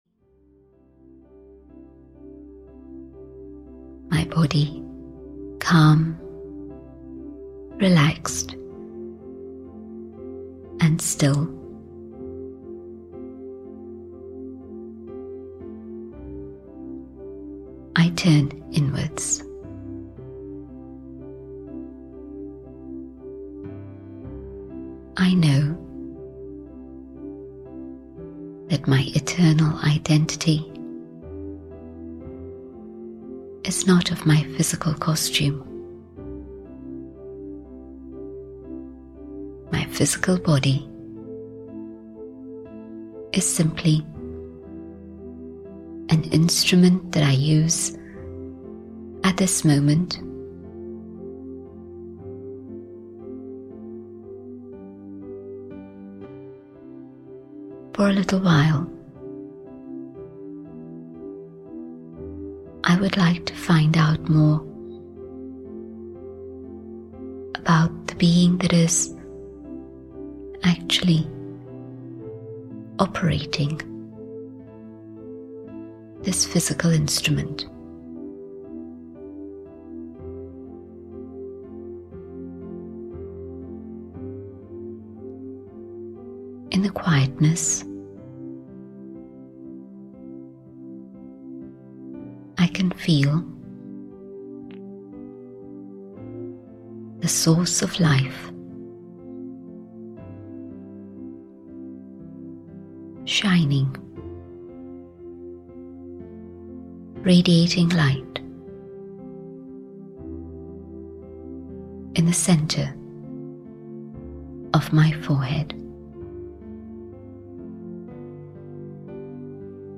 Knowing Myself (EN) audiokniha
Ukázka z knihy